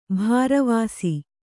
♪ bhāravāsi